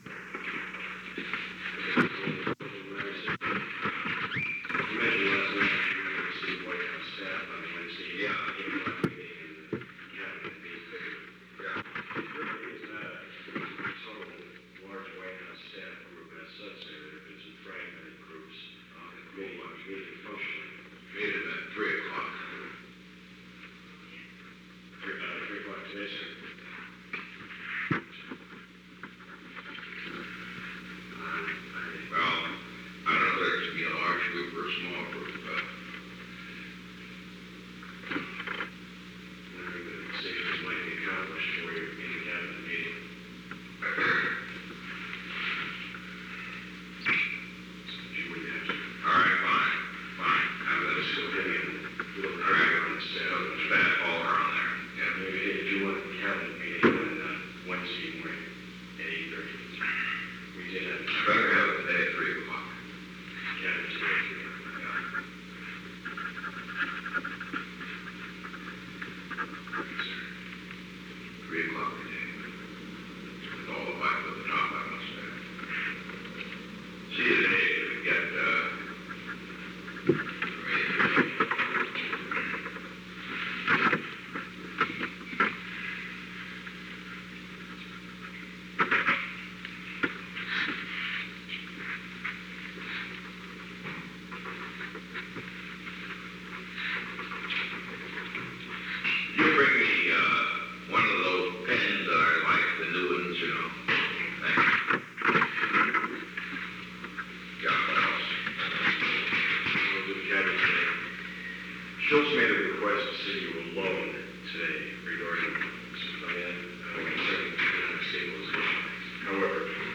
Secret White House Tapes
Conversation No. 908-1
Location: Oval Office
conversation was in progress.
An unknown woman entered the room at an unknown time after 5:59 am.